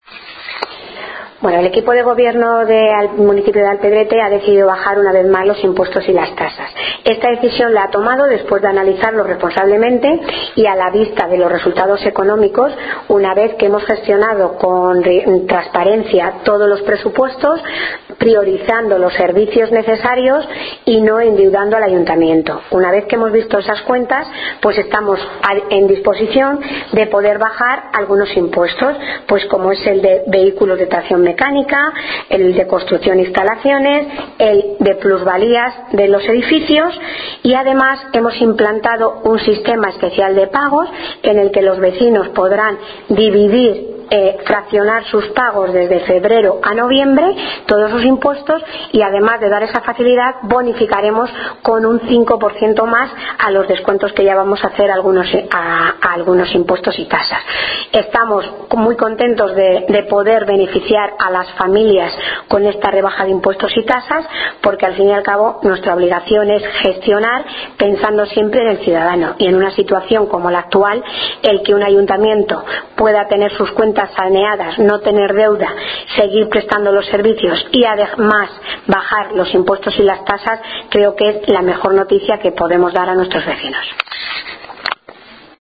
La Alcaldesa María Casado explica esta decisión, “tomada con mucha responsabilidad, y que ha sido posible gracias al trabajo de años anteriores, cuando hemos sabido ser rigurosos y transparentes en nuestras gestiones, sin endeudar al Ayuntamiento y priorizando las necesidades de los vecinos”.
mariacasado-bajadaimpuestos.mp3